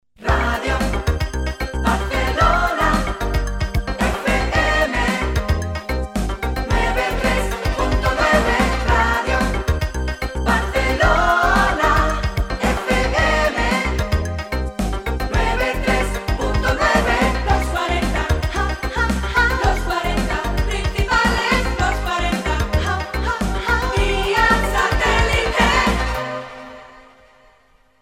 Identificació cantada